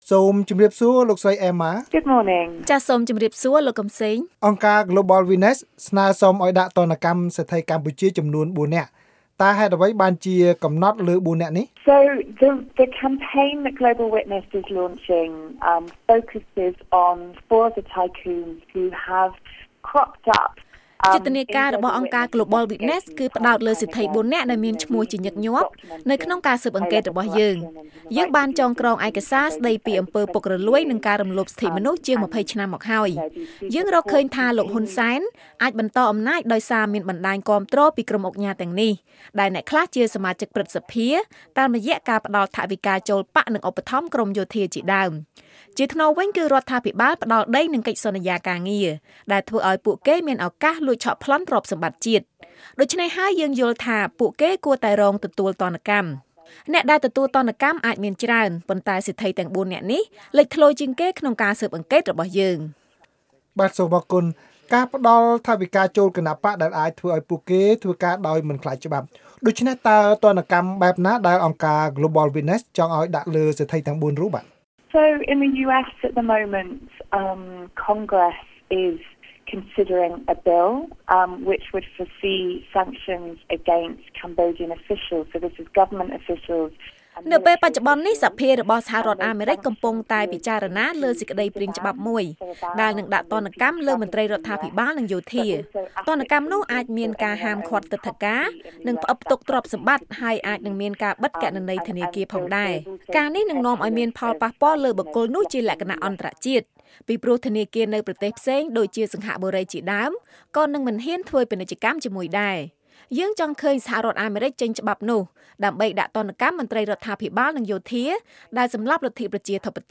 បទសម្ភាសន៍VOA៖ អង្គការGlobal Witness ស្នើសហរដ្ឋអាមេរិកដាក់ទណ្ឌកម្មសេដ្ឋី៤នាក់